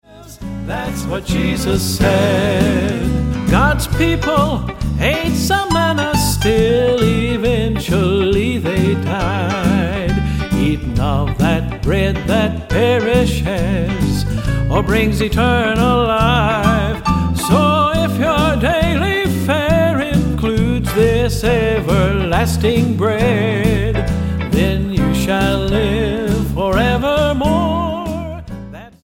STYLE: Southern Gospel